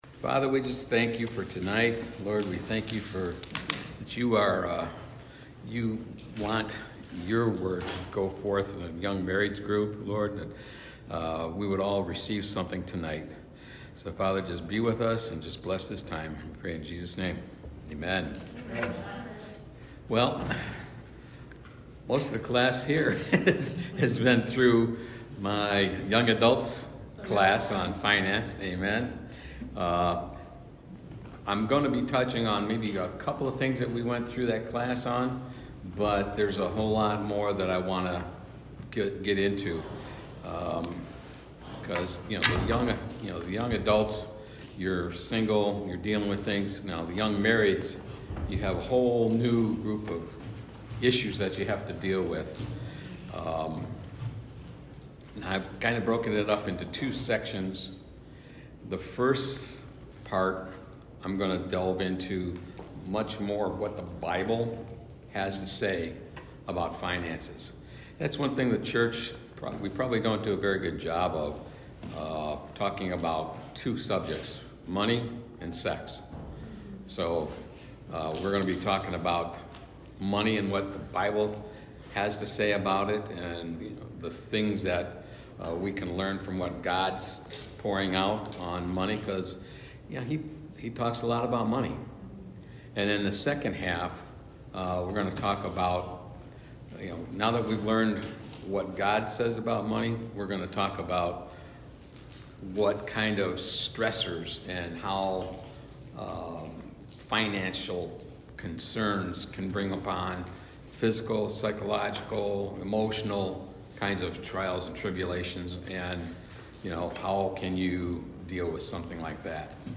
God's Perfect Plan Service Type: Class Week 9 of God’s Perfect Plan